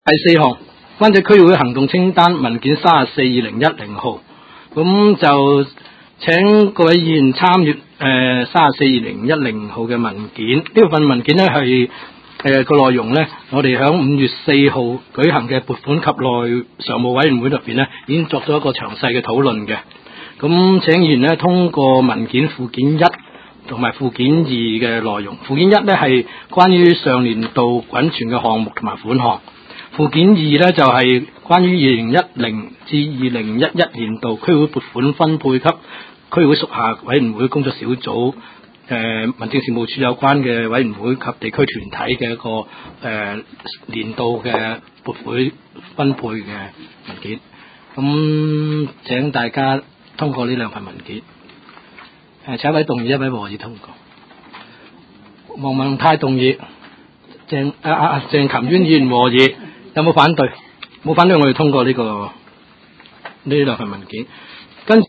灣仔區議會第十六次會議
灣仔民政事務處區議會會議室